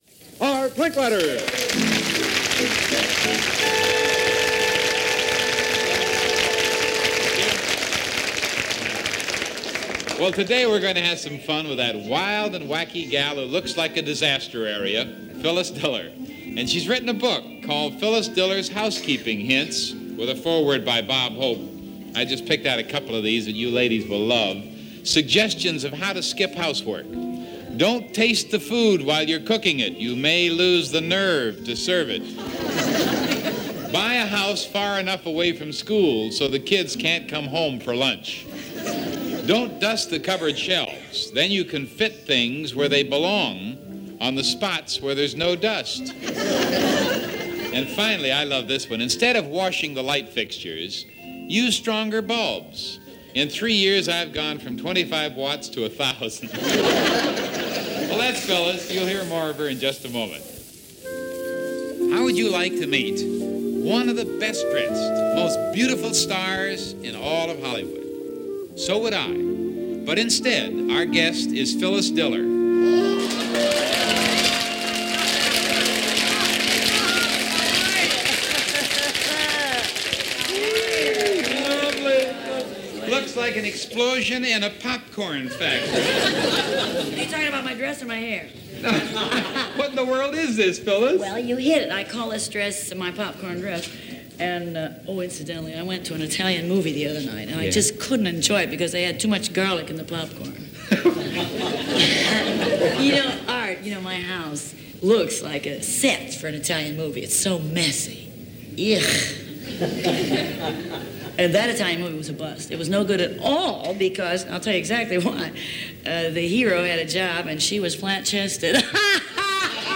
Phyllis Diller – chats with Art Linkletter – Art Linkletter’s House Party – CBS Radio – Jan. 19, 1967 – Gordon Skene Sound Collection.
Here is Phyllis Diller, as interviewed by Art Linkletter from House Party – January 19, 1967 from the CBS Radio Network.